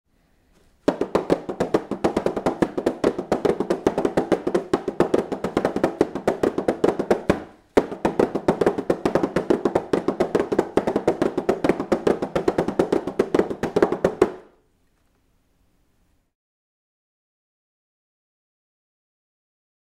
PANDEIRO_441.mp3